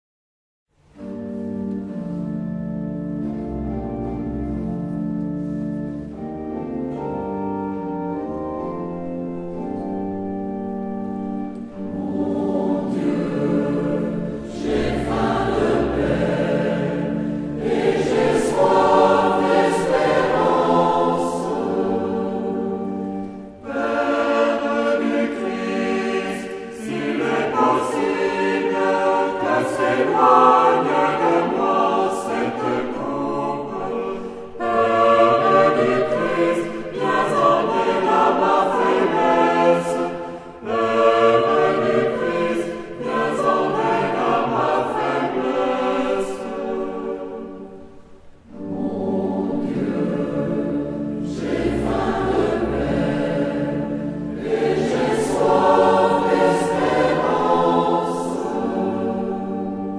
Epoque : 20ème s.
Genre-Style-Forme : Cantique ; Sacré
Type de choeur : SATB  (4 voix mixtes )
Tonalité : sol mineur